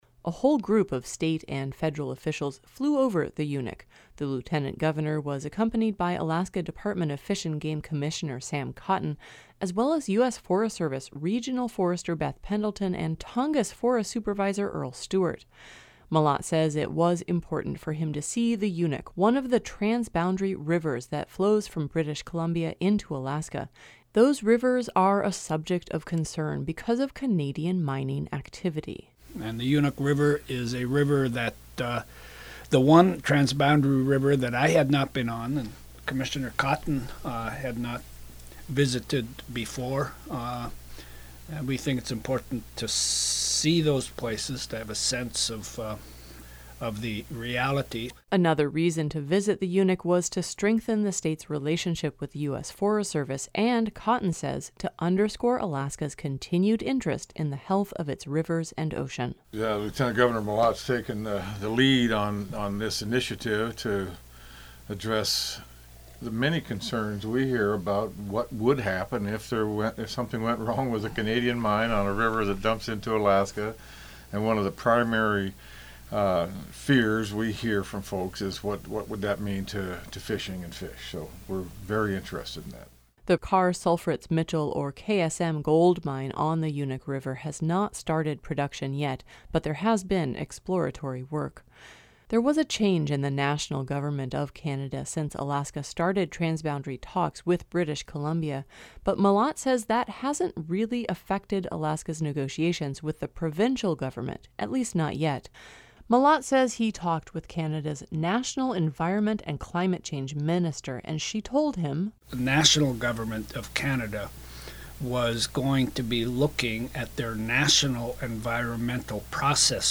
Following his trip to the Unuk, and to the small boundary community of Hyder, Mallott came by KRBD to talk about how transboundary mine negotiations are progressing.